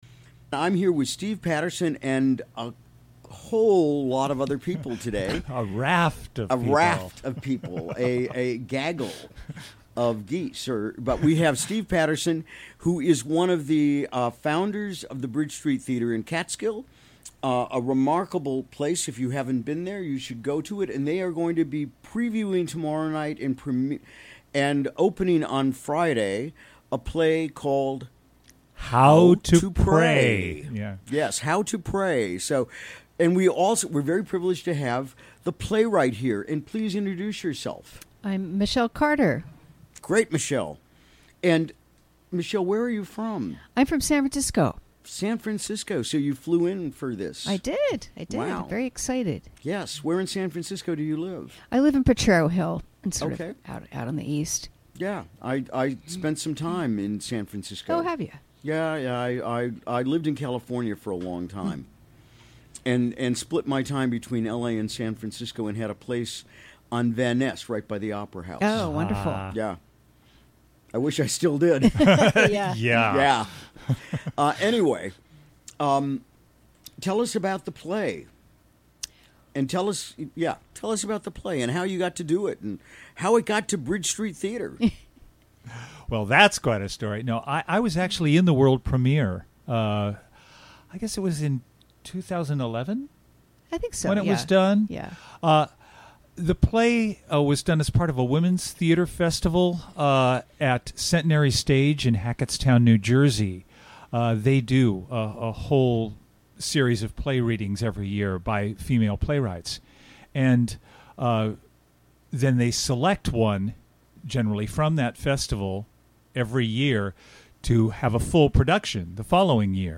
Recorded during the WGXC Morning Show on Wednesday, September 13, 2017.